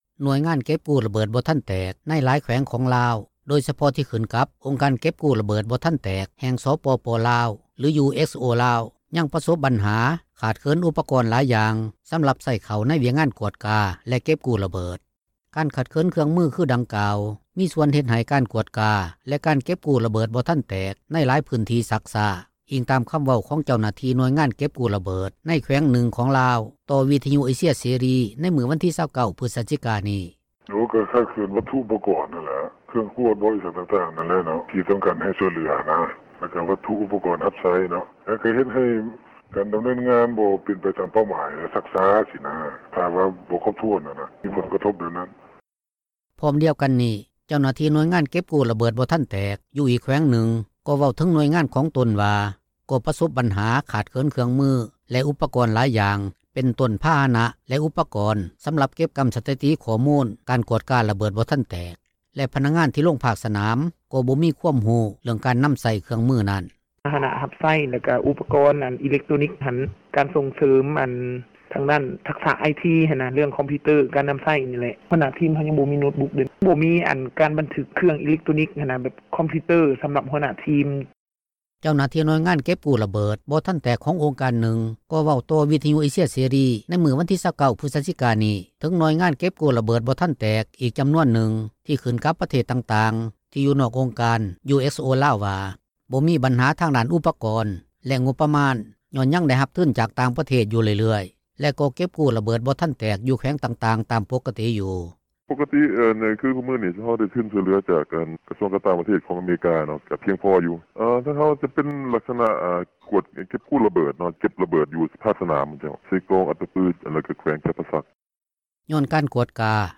ດັ່ງພະນັກງານເກັບກູ້ລະເບີດບໍ່ທັນແຕກ ນາງນຶ່ງເວົ້າວ່າ: